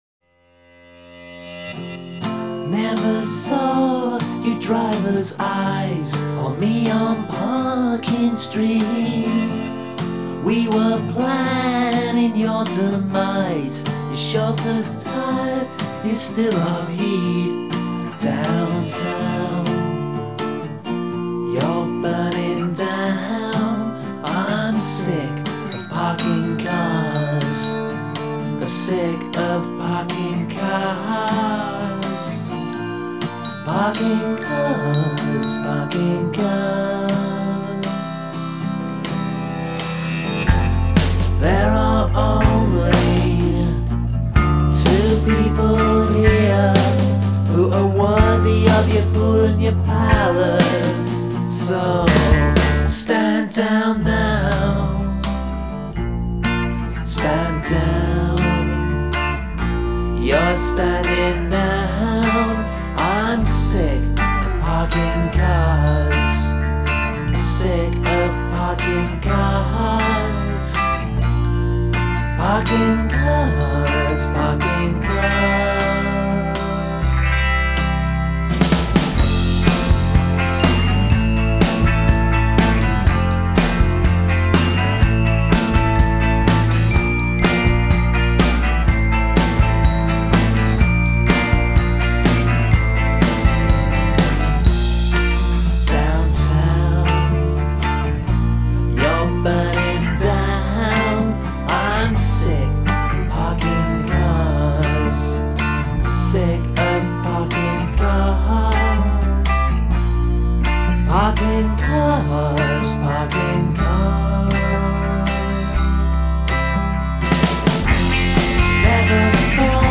Жанр: alternative rock, indie pop